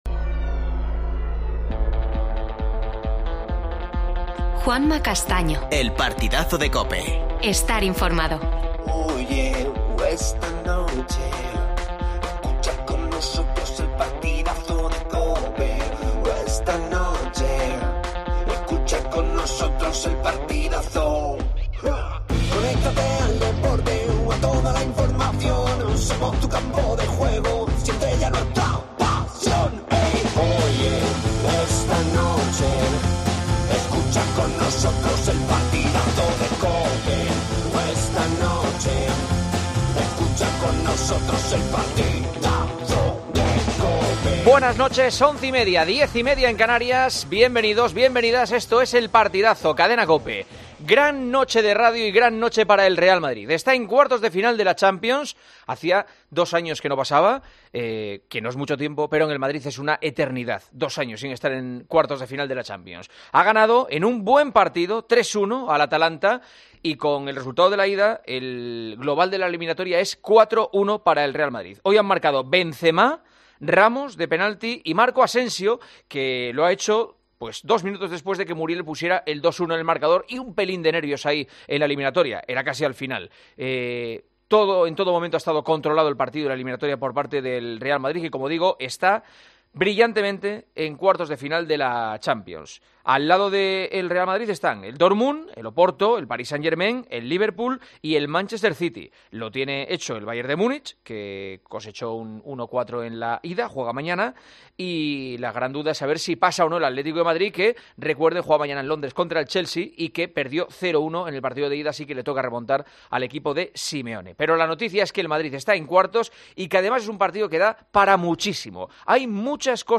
El Real Madrid, a cuartos de la Champions League. Escuchamos a Ramos, Vinicius, Lucas Vázquez y Zidane.